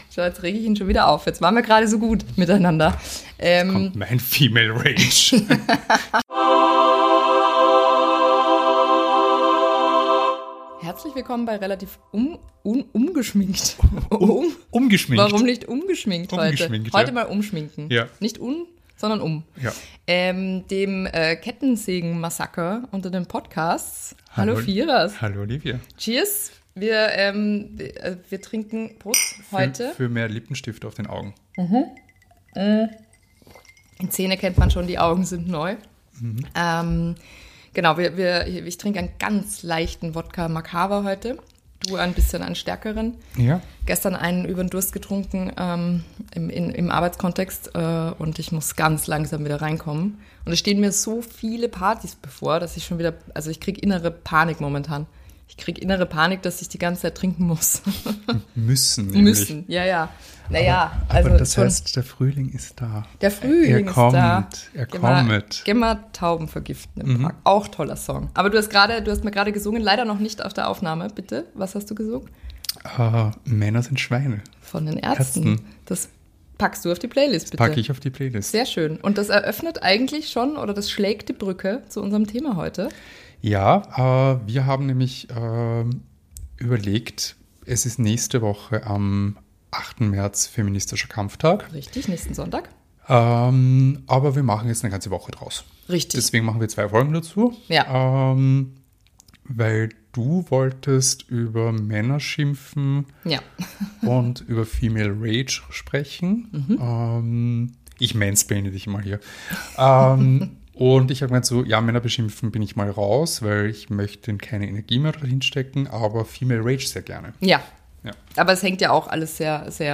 Intro: Sound Effect